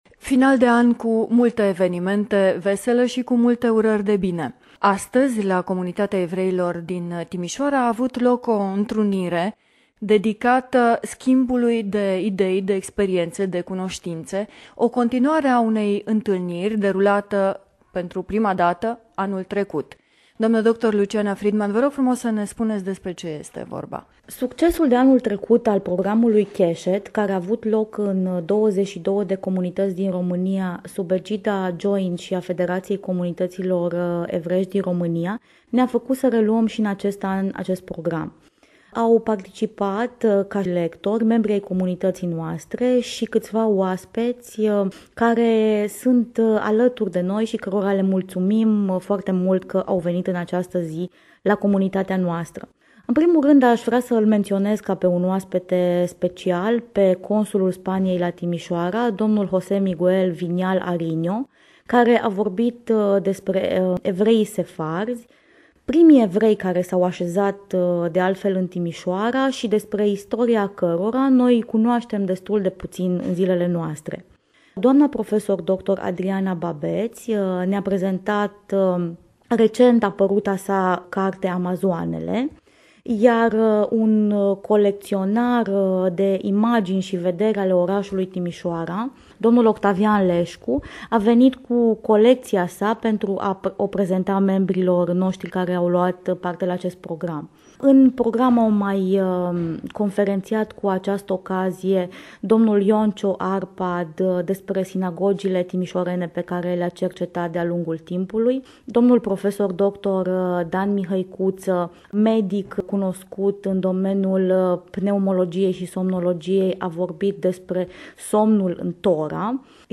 Emisiunea radio "Convietuiri ebraice" Interviu